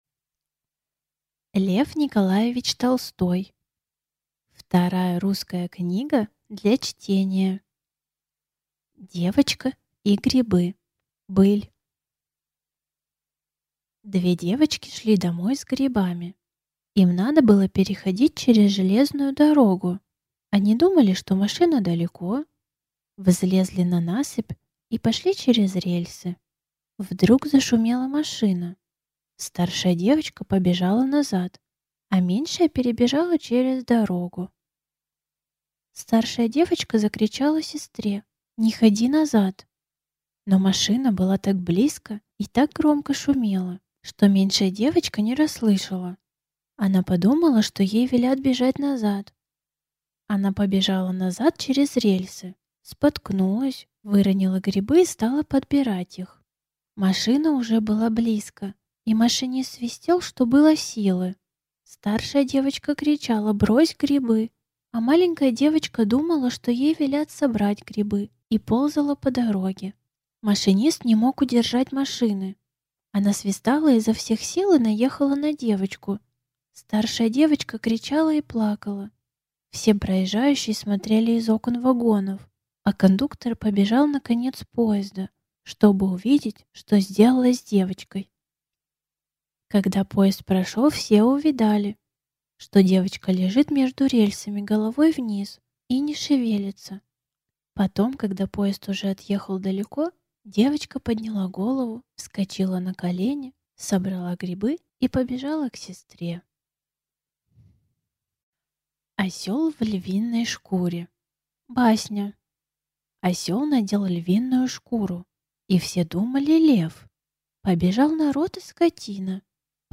Аудиокнига Вторая русская книга для чтения | Библиотека аудиокниг